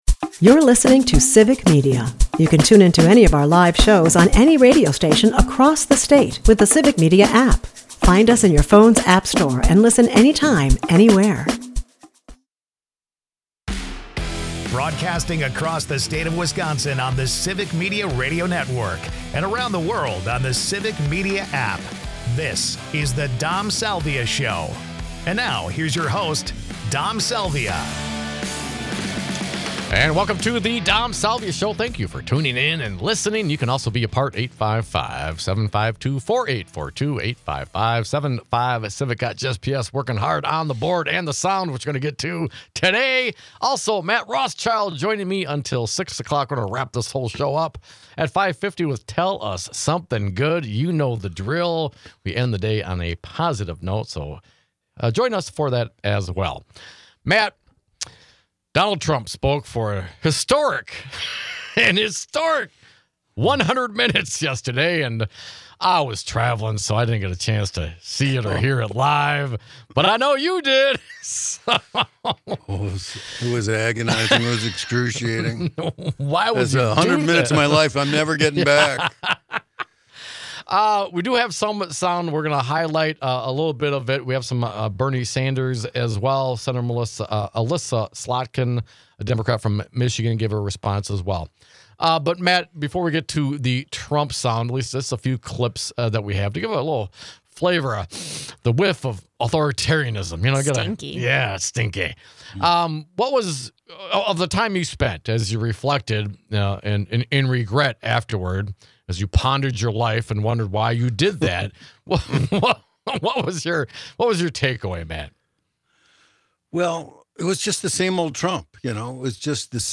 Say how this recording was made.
Listen throughout the state of Wisconsin on the Civic Media network and worldwide on the Civic Media app.